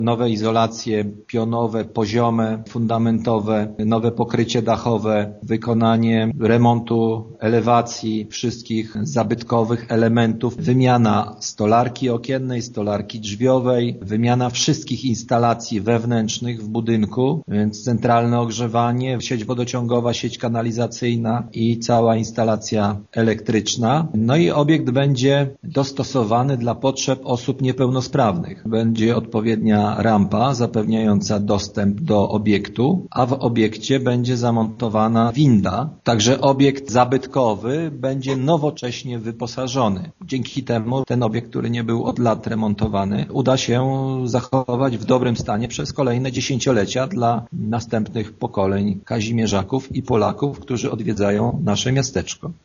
Dzięki dofinansowaniu, jakie miasto pozyskało z Unii Europejskiej, obiekt zostanie zmodernizowany, odnowiony i dostosowany do potrzeb osób niepełnosprawnych – informuje burmistrz Kazimierza Dolnego Grzegorz Dunia: